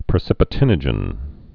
(prĭ-sĭpĭ-tĭnə-jən)